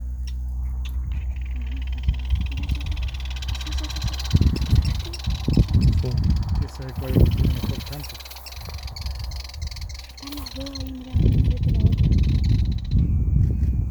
João-da-palha (Limnornis curvirostris)
Nome em Inglês: Curve-billed Reedhaunter
Localidade ou área protegida: Ruta Provincial 46 entre Villa Paranacito y RN14
Condição: Selvagem
Certeza: Fotografado, Gravado Vocal
Pajonalera-pico-curvo_1.mp3